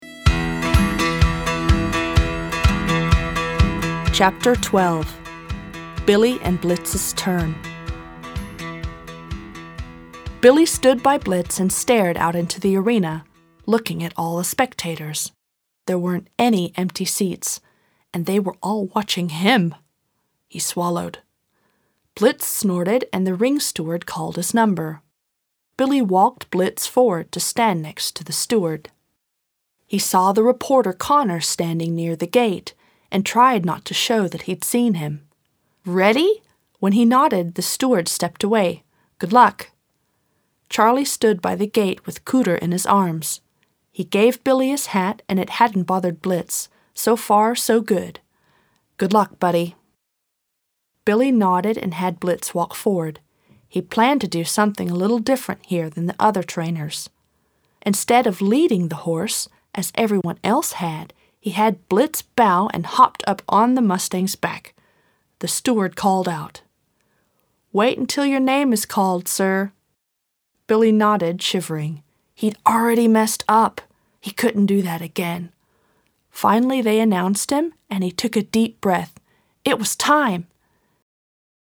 Voice over native English speaker living in Berlin, Germany.
englisch (us)
Sprechprobe: Sonstiges (Muttersprache):